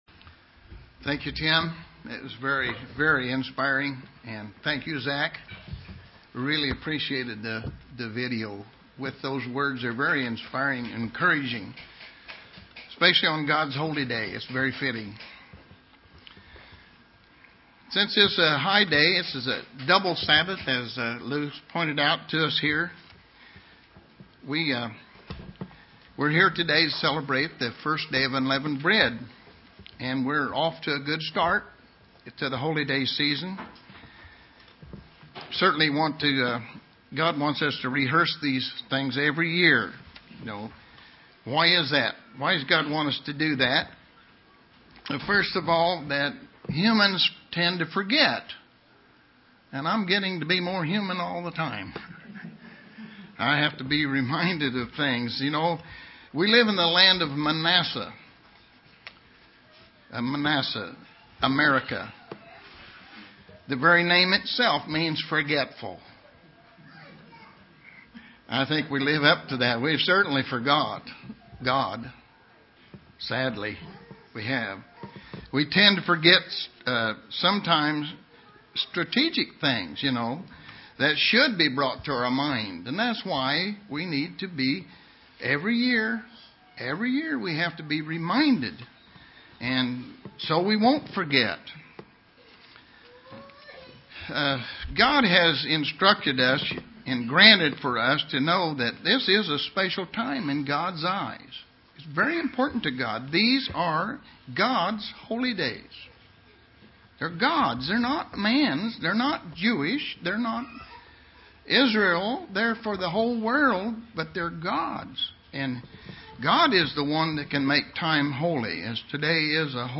Given in Terre Haute, IN
UCG Sermon Studying the bible?